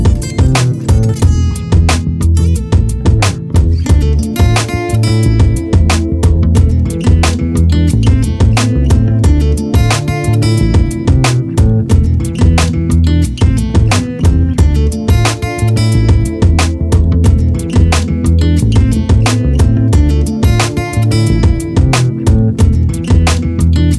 no Backing Vocals R'n'B